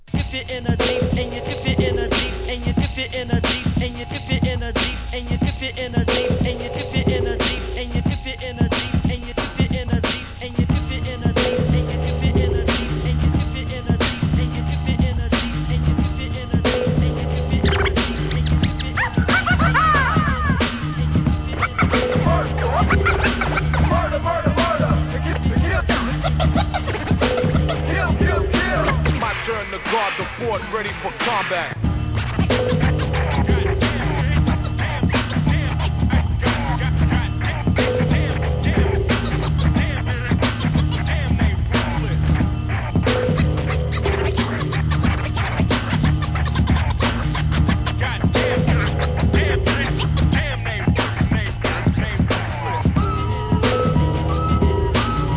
HIP HOP/R&B
HIP HOP CLASSICの数々を駆使したBEATにスクラッチをはめ込んでいくブレイクビーツ集!!!